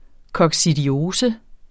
coccidiose substantiv, fælleskøn Bøjning -n Udtale [ kʌgsidiˈoːsə ] Oprindelse sammensat af coccidier og -ose Betydninger tarmsygdom hos husdyr og vildt, fremkaldt af coccidier Se også coccidier Tynd grøn afføring og en krop som en spærreballon på ben.